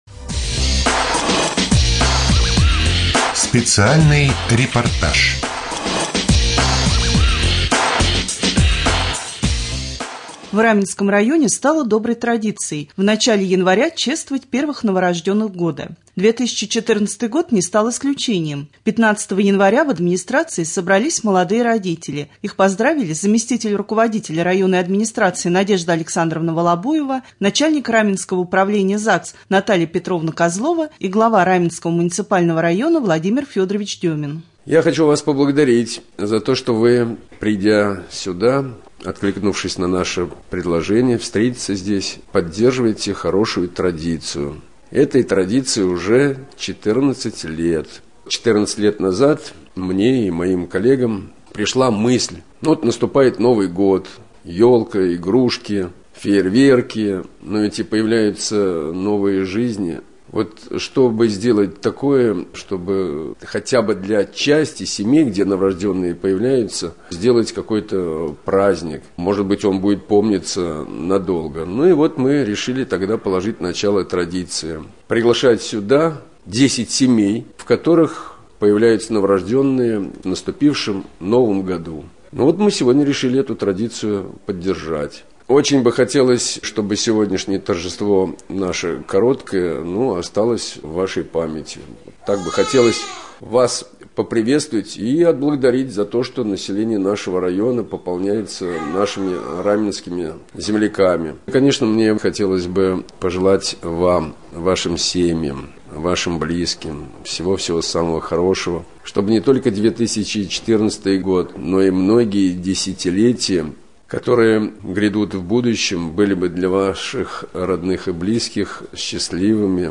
16.01.2014г. в эфире раменского радио - РамМедиа - Раменский муниципальный округ - Раменское
Рубрика «Специальный репортаж». В администрации Раменского района прошло чествование родителей 10 первых родившихся малышей в 2014 году.